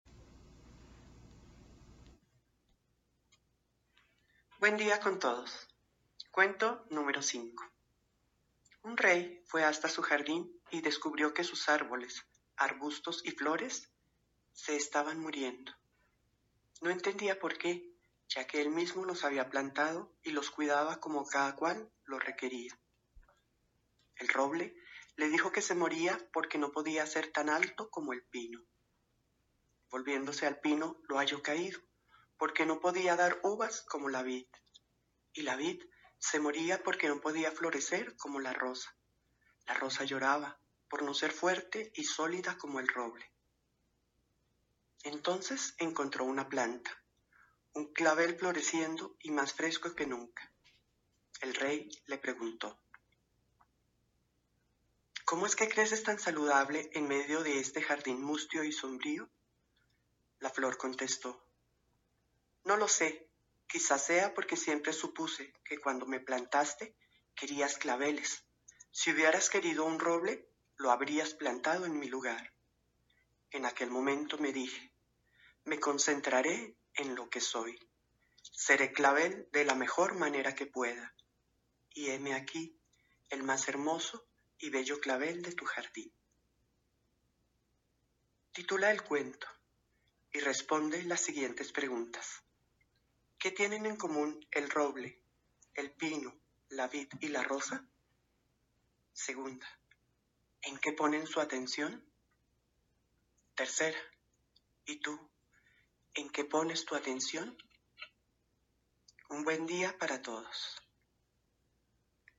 Los hermanos del Colegio Valores Humanos Sathya Sai de Funza (Colombia) han tenido la magnífica inspiración de grabar Cuentos de Valores Humanos para trabajar en casa mientras dure el período de aislamiento social.